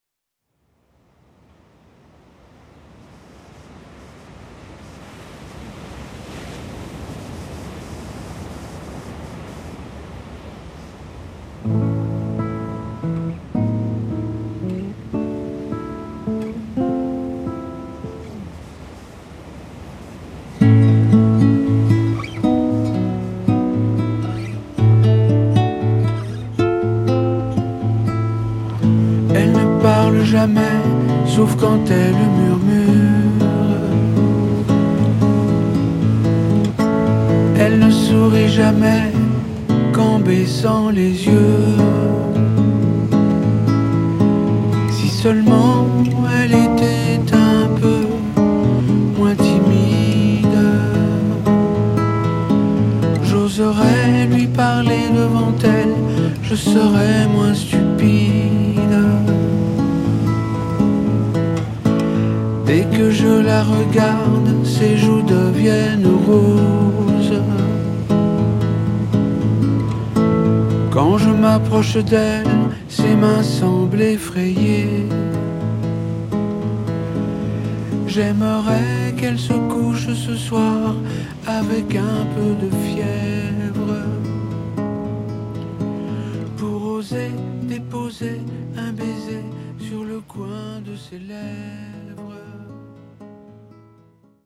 tonalité LA majeur